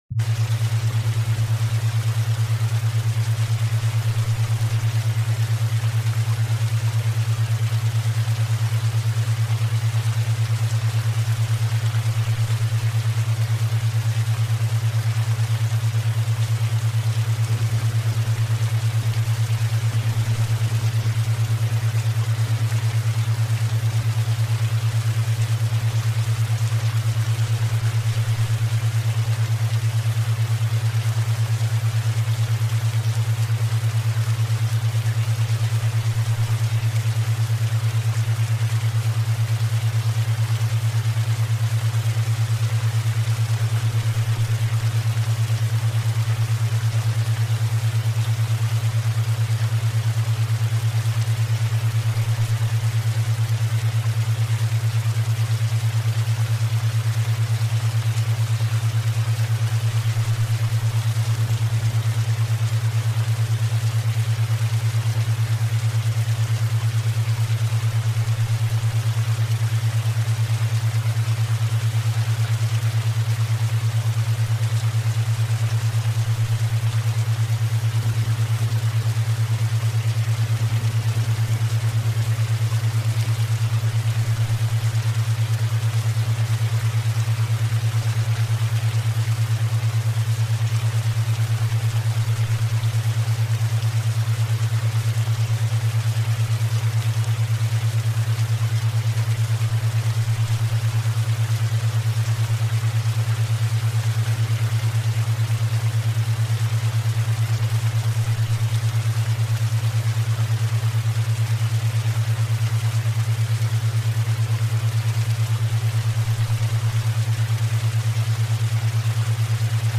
Guérison Totale : Fréquences